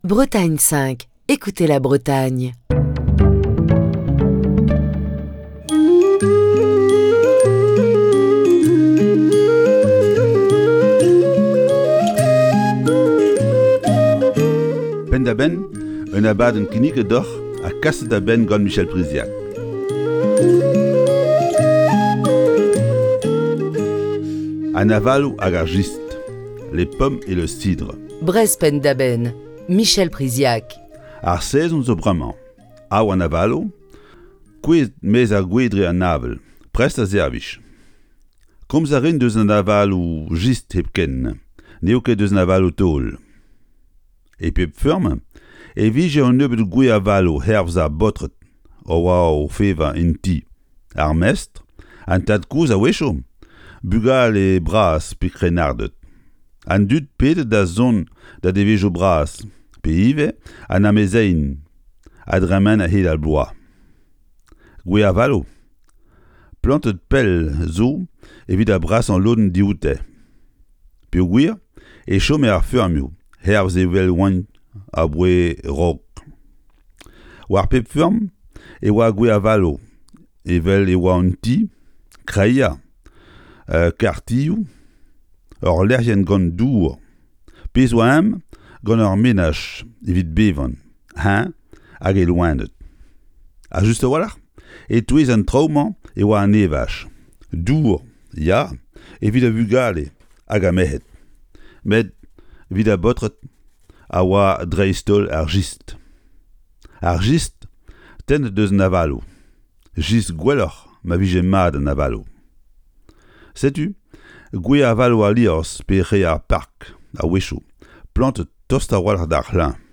Chronique du 15 novembre 2021.